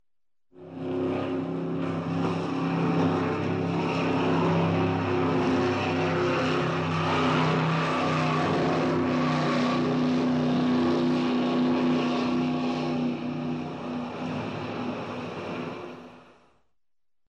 Звук скоростного катера: проезд
Корабли, лодки, катера